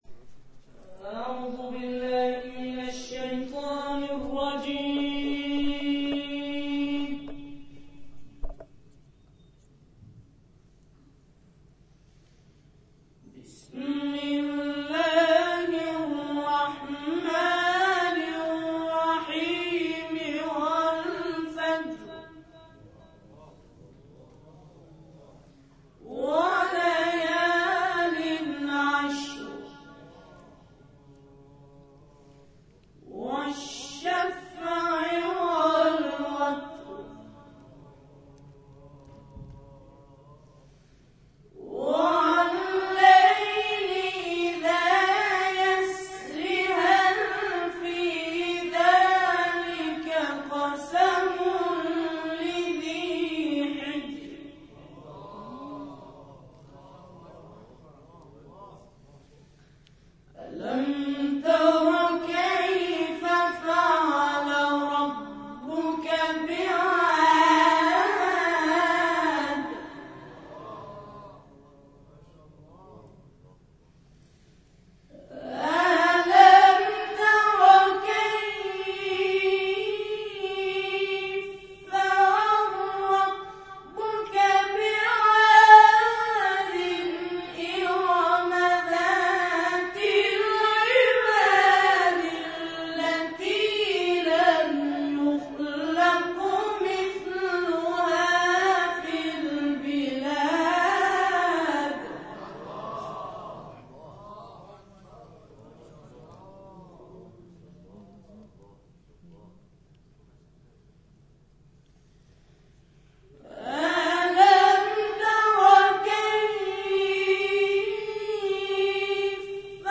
در ادامه این همایش، گروه همخوانی «سبیل الرشاد» به همخوانی سوره‌های فجر، بلد و انشراح پرداختند و مورد تشویق حاضران در جلسه قرار گرفتند.
در پایان تلاوت قرآن و همخوانی گروه سبیل‌الرشاد ارائه می‌شود.